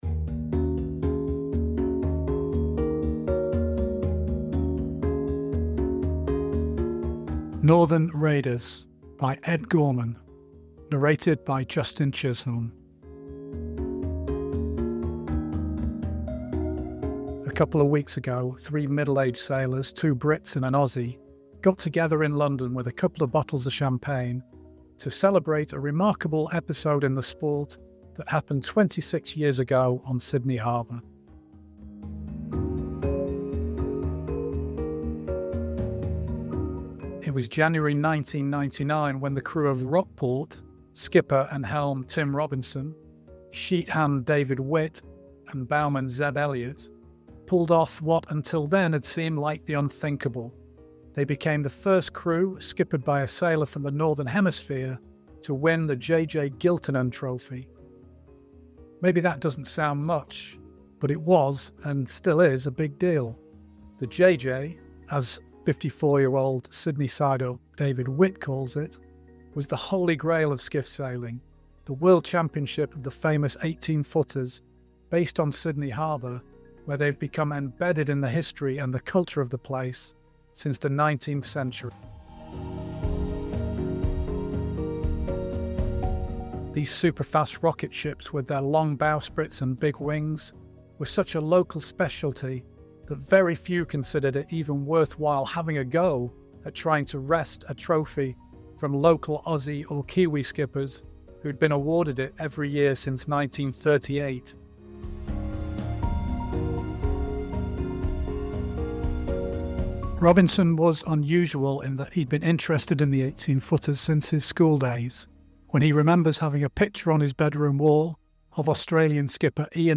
1 Narrated story: Northern Raiders 11:32